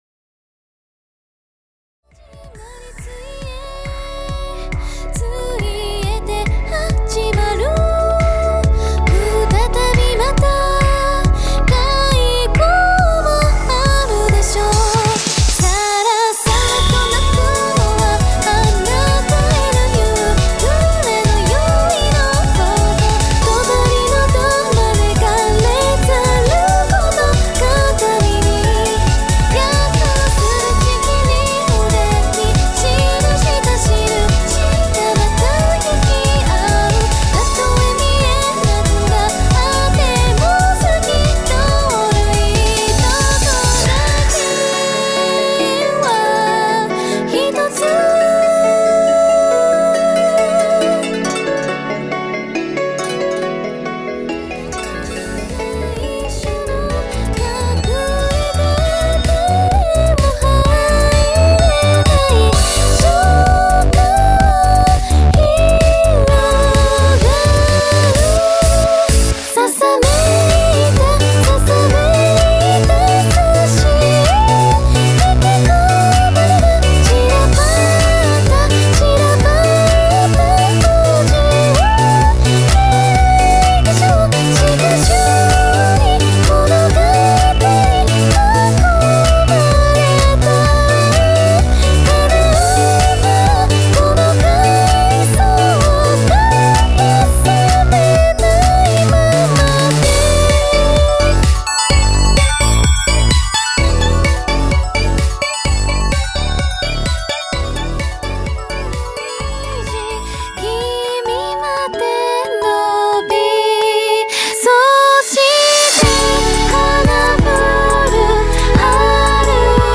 日付的には明日というギリギリ感満載ですがクロスフェード版でございます。
クロスフェード版はCD用にマスタリングされたものではないのでCDに収録するものとは若干仕様が異なりますー！
普段はベーシストな僕ですが今作では生楽器要素は一切ありません。
自分の中に制約を設けての実験的で自分らしさが出たほんのり幻想的な作品かと思います。